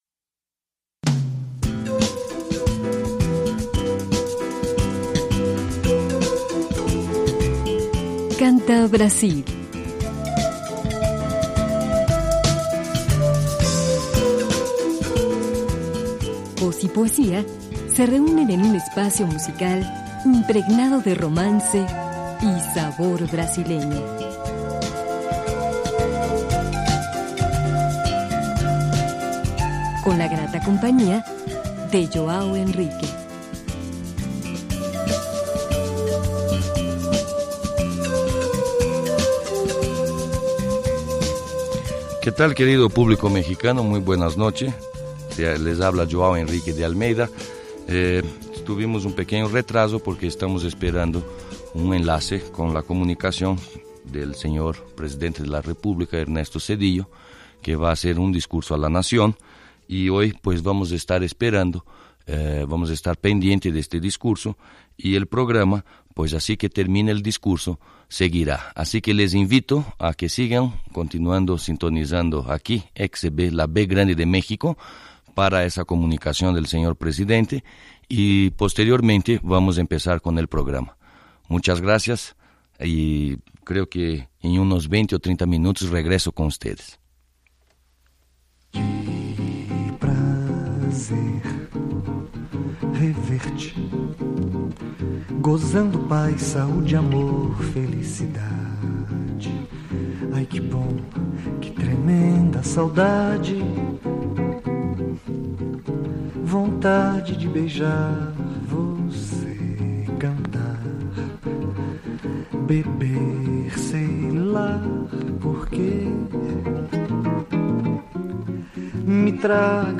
transmitido en 1994.